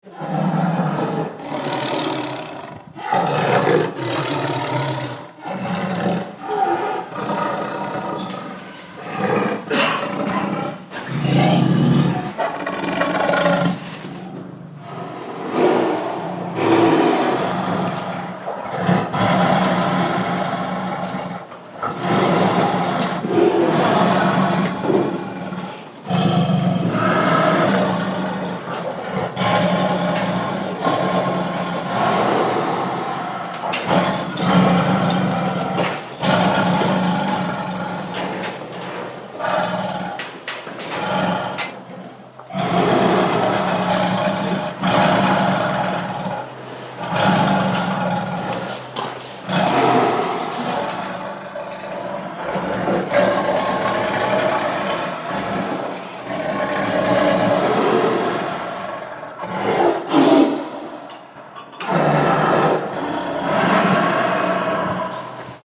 Angry Tiger Téléchargement d'Effet Sonore
Angry Tiger Bouton sonore